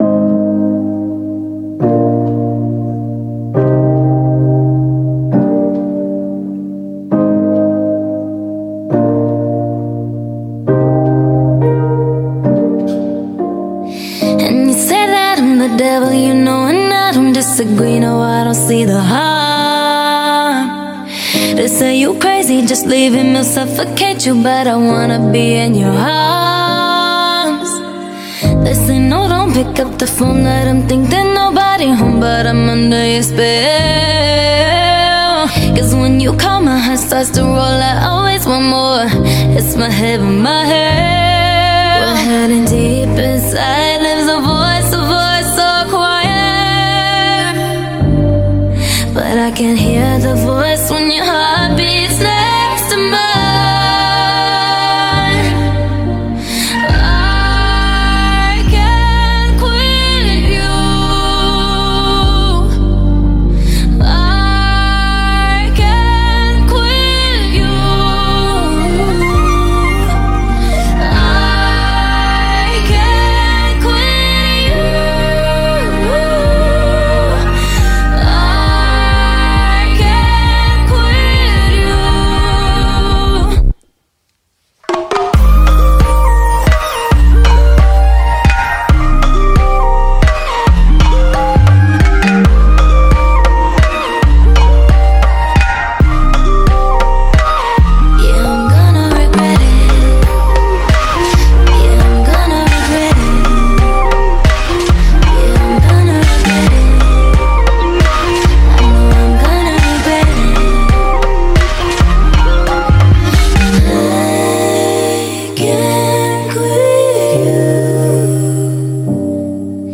BPM68-135
Audio QualityMusic Cut
Very melodic and euphoric.